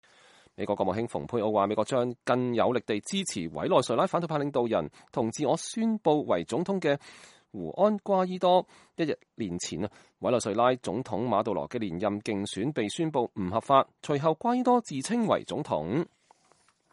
星期一在哥倫比亞波哥大舉行的地區性反恐會議期間，蓬佩奧和瓜伊多會晤並舉行記者會。